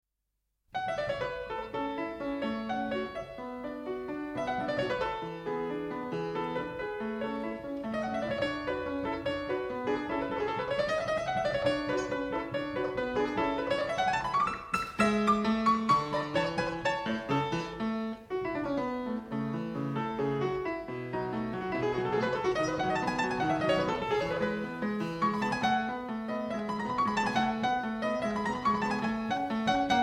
RECORDED IN MOZART'S BIRTHPLACE ON MOZART'S OWN FORTEPIANO,